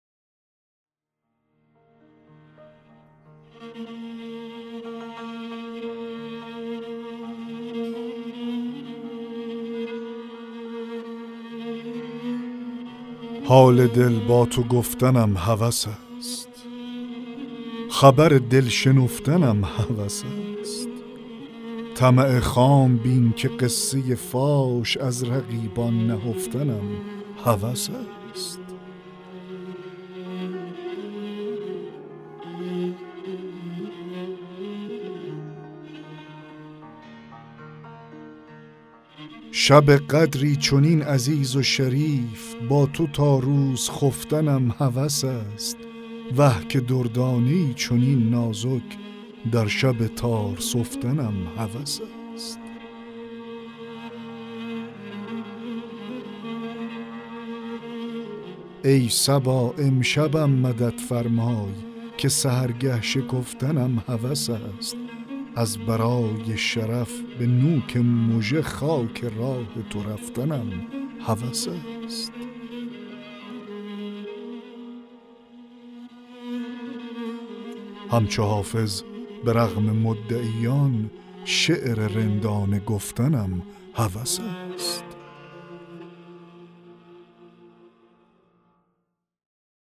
دکلمه غزل 42 حافظ
دکلمه-غزل-42-حافظ-حال-دل-با-تو-گفتنم-هوس-است.mp3